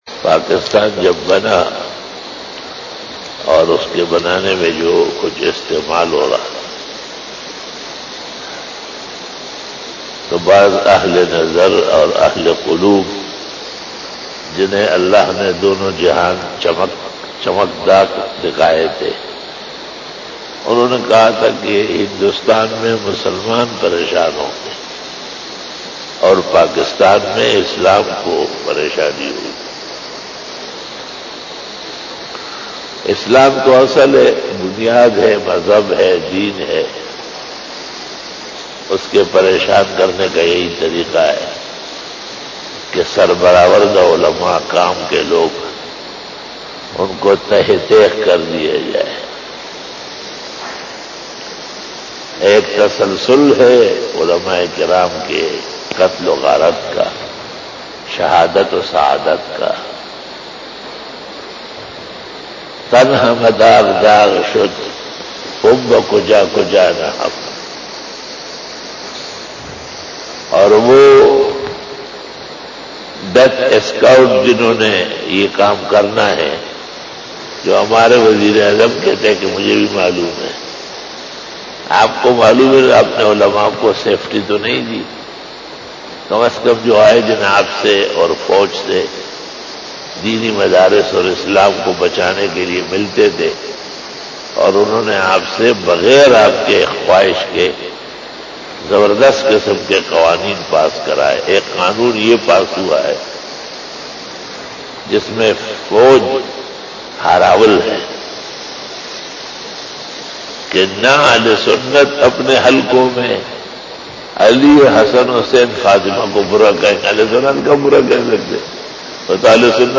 After Namaz Bayan
Fajar bayan